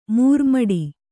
♪ mūrmaḍi